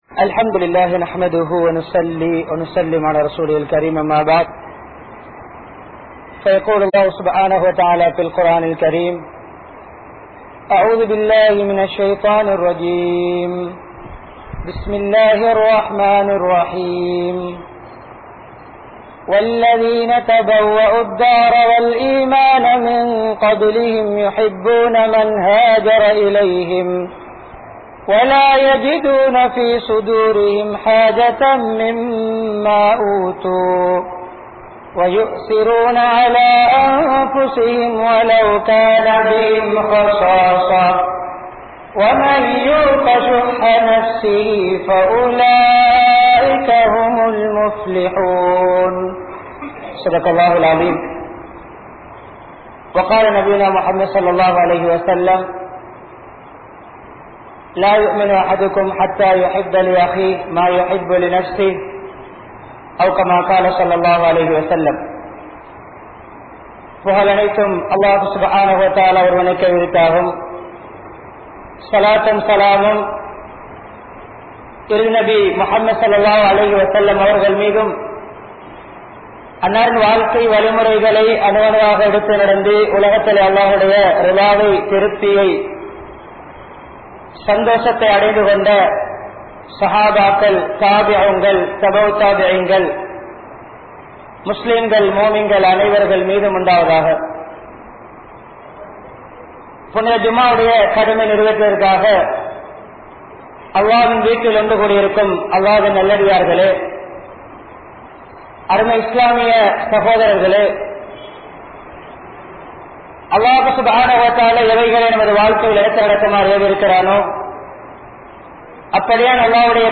SuyaNalavaathien Iruthi Nilai (சுயநலவாதியின் இறுதி நிலை) | Audio Bayans | All Ceylon Muslim Youth Community | Addalaichenai
Kurunegala, Mallawapitiya Jumua Masjidh